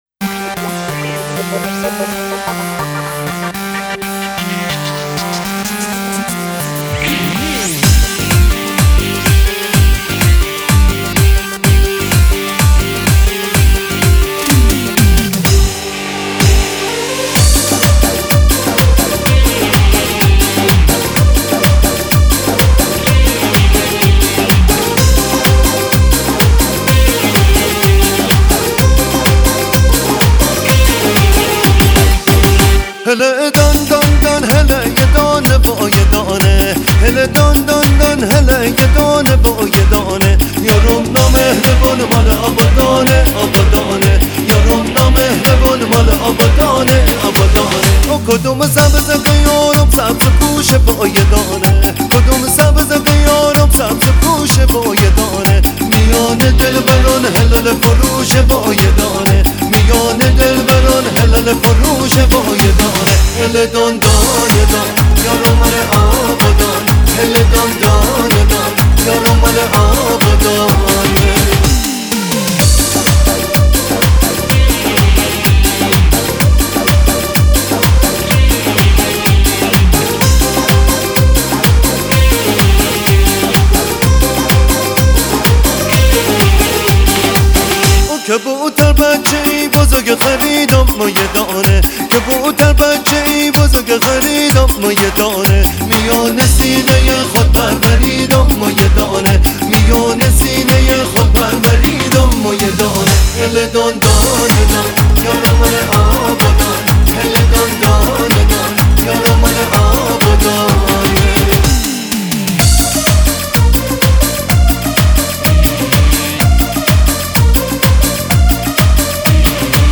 ریمیکس با صدای مرد